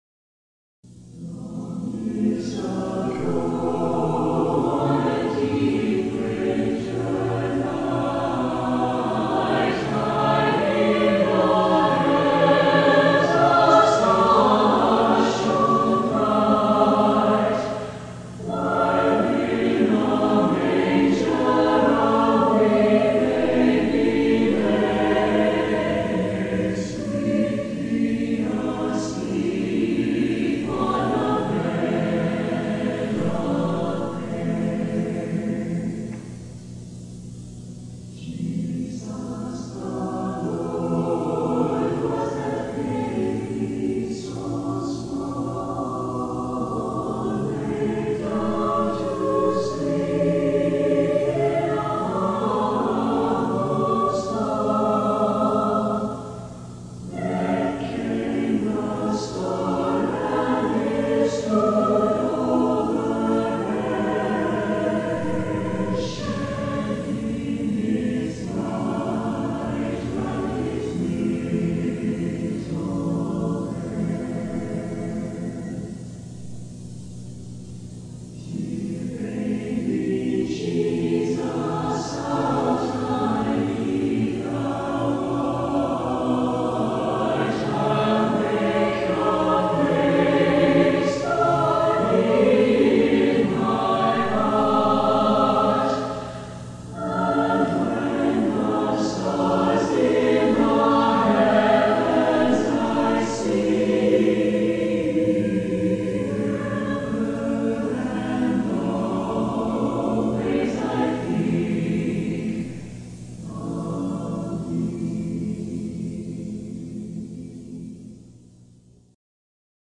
Southern Nazarene University presents the SNU Choral, Brass Choir and Choral Society in their annual Christmas concert : Christmas Sounds.